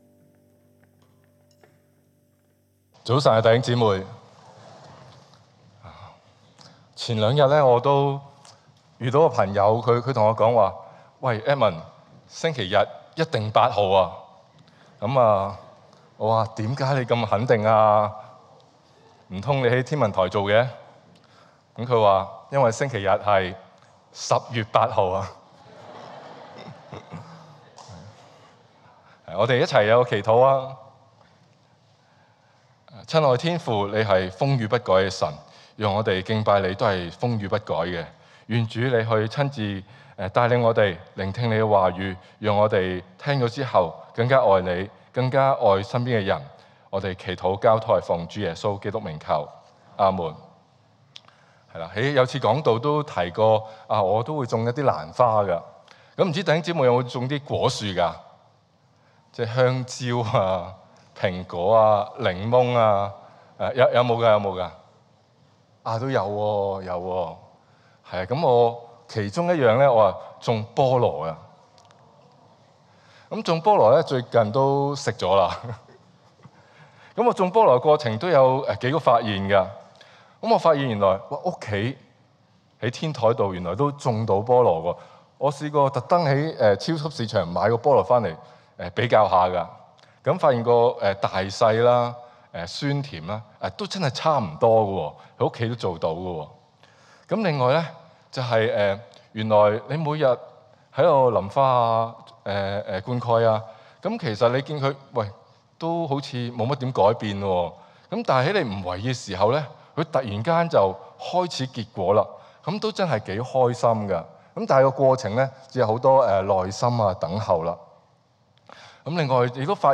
证道集